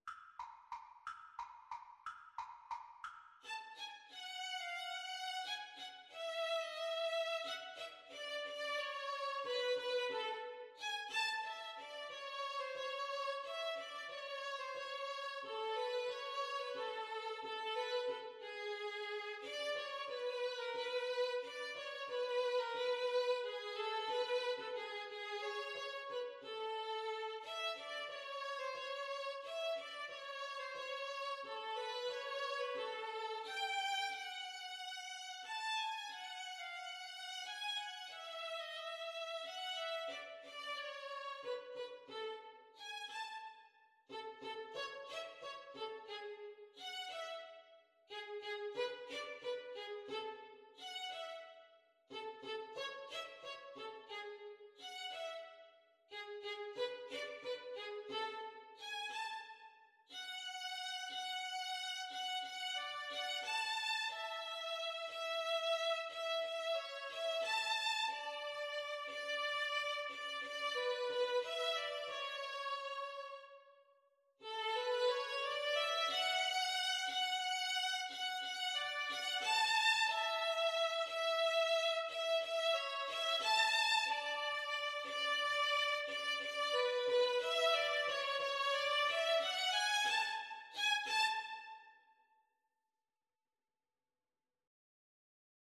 Violin 1Violin 2
One in a bar .=c.60
3/4 (View more 3/4 Music)